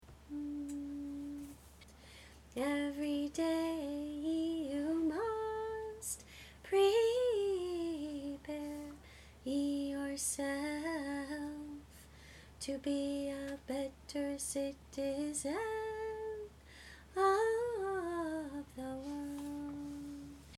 See individual song practice recordings below each score.
Many, especially the ones below the individual scores, were recorded on an old, portable cassette tape and have some distortion.